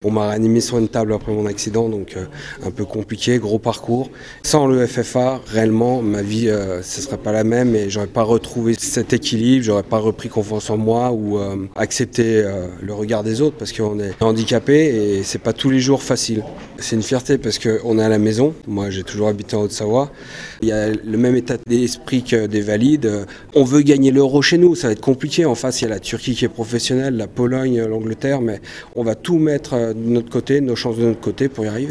un des joueurs de l’EFFA